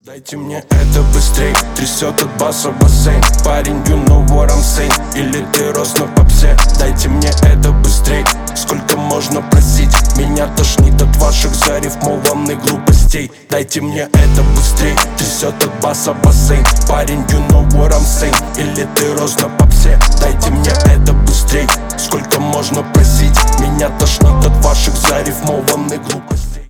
басы
рэп